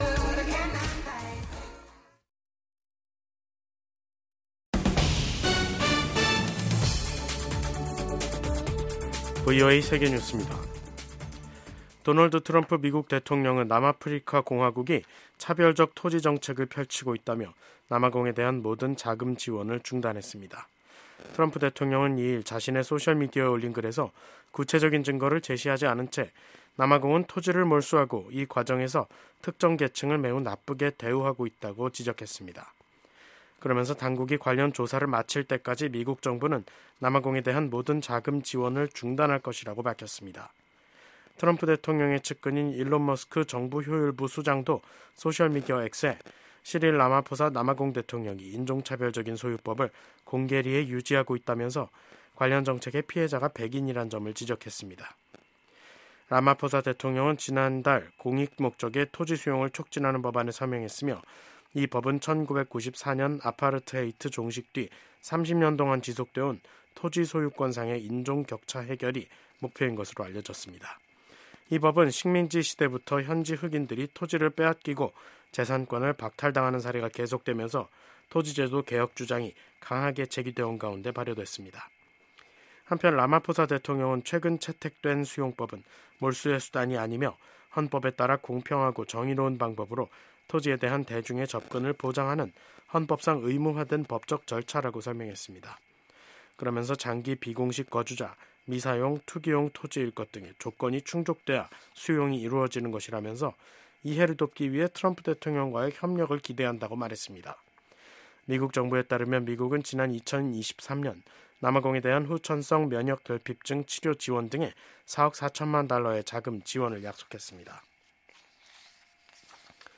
VOA 한국어 간판 뉴스 프로그램 '뉴스 투데이', 2025년 2월 3일 3부 방송입니다. 마르코 루비오 국무장관이 미국이 세계 모든 문제를 짊어지는 현 상황을 비정상적이라고 평가하며 외교 초점을 ‘미국의 이익’에 맞출 것이라고 밝혔습니다. 루비오 국무장관이 불량국가라고 언급한 것에 대한 반응으로 북한은 도널드 트럼프 행정부 출범 이후 첫 대미 비난 담화를 냈습니다.